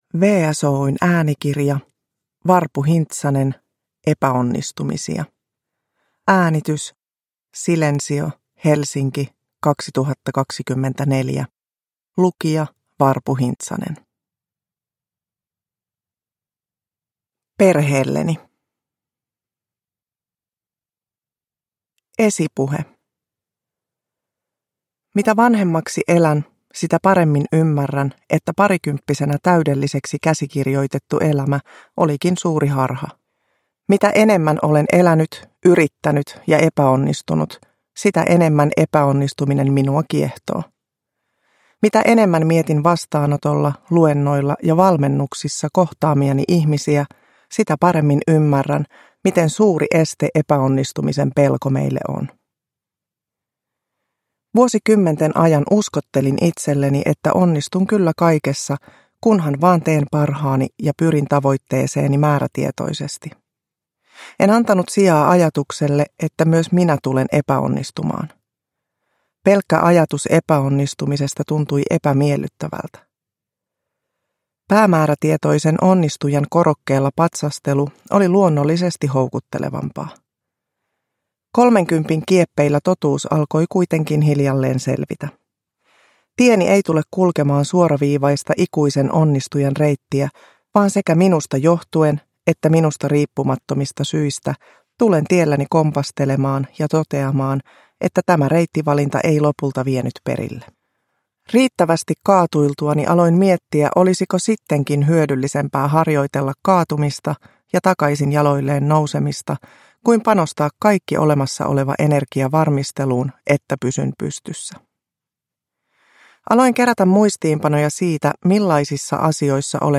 Epäonnistumisia – Ljudbok